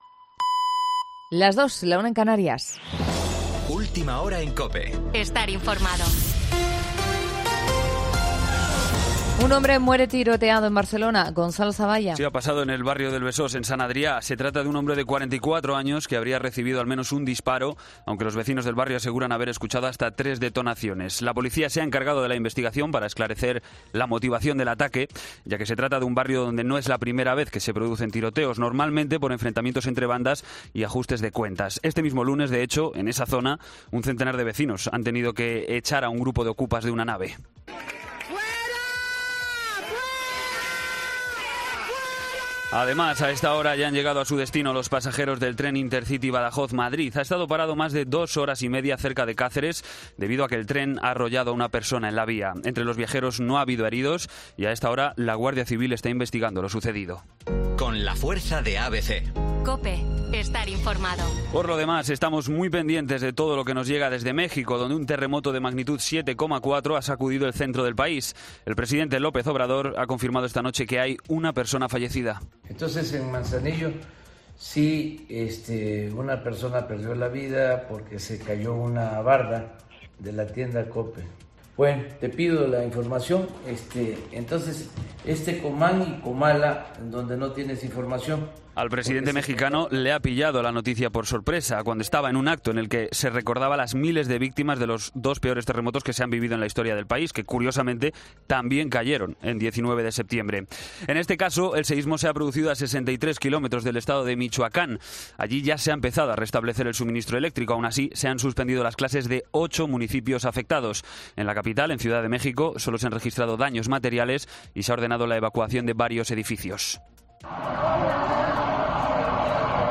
Boletín de noticias COPE del20 de septiembre a las 02:00 horas
AUDIO: Actualización de noticias Herrera en COPE.